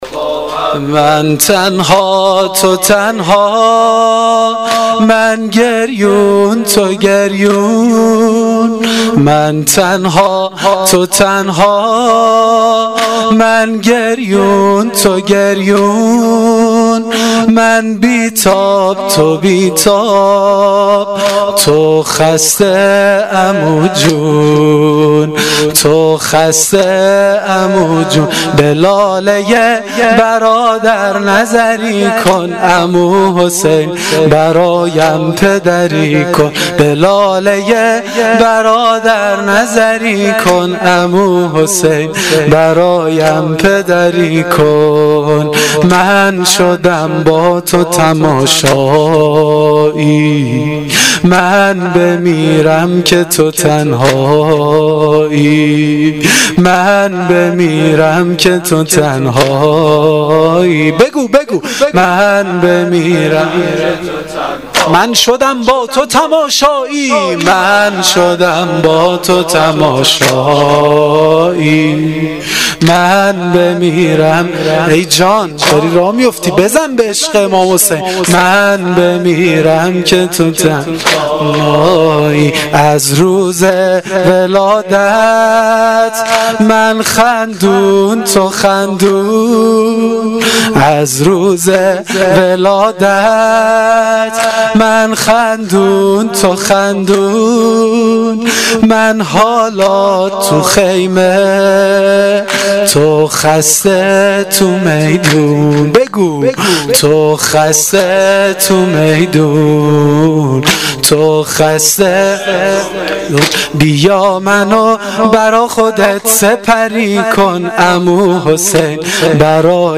واحد شب ششم محرم الحرام 1396